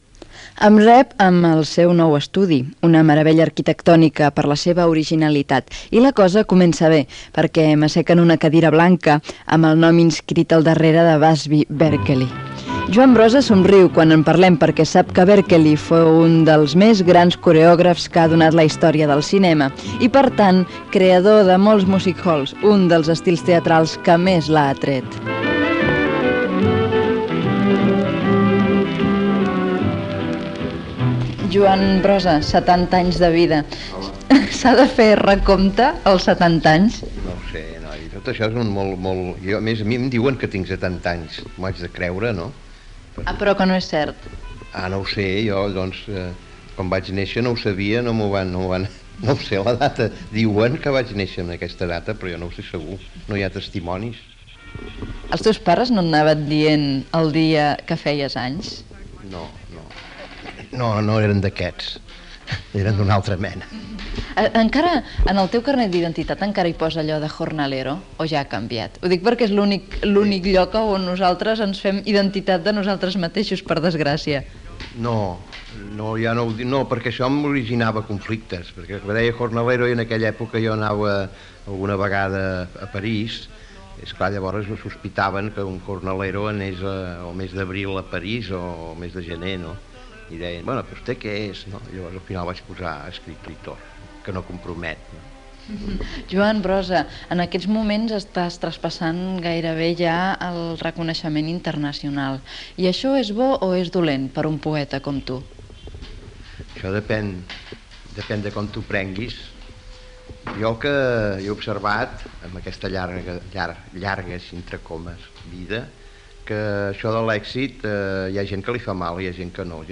57ffba6f235b07b69279bfff1f13c2c53169af54.mp3 Títol Cadena Catalana Barcelona Emissora Cadena Catalana Barcelona Cadena Cadena Catalana Titularitat Privada estatal Descripció Entrevista a l'escriptor i poeta Joan Brossa quan tenia 70 anys. S'hi parla de la seva obra i dels seus refents creatius